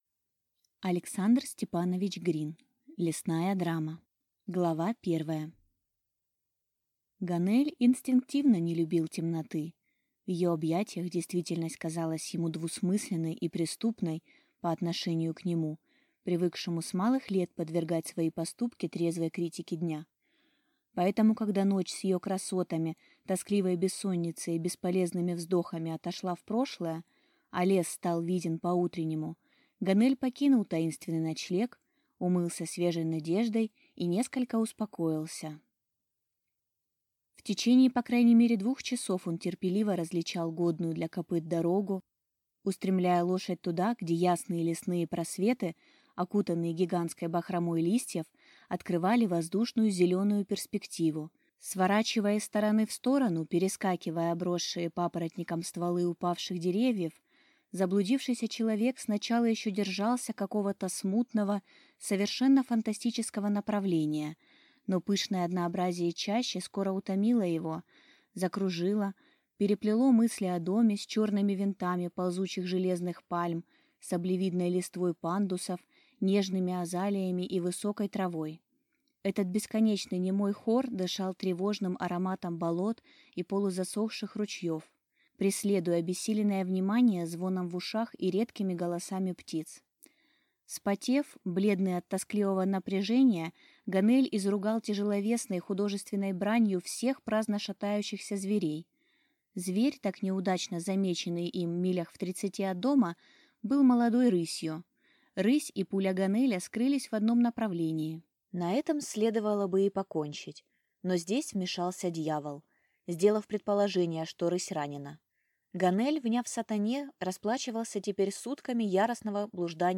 Аудиокнига Лесная драма | Библиотека аудиокниг